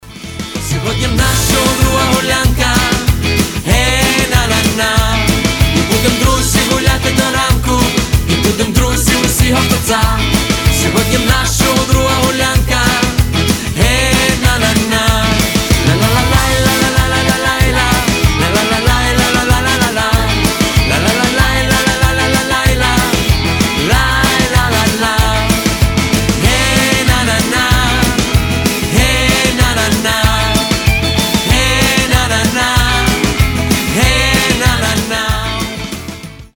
• Качество: 320, Stereo
позитивные
труба
застольные